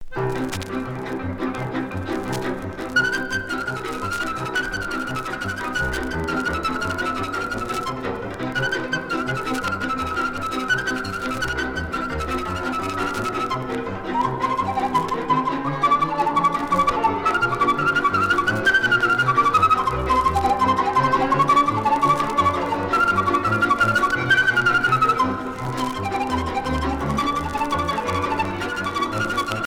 danse : hora (Roumanie)
Pièce musicale éditée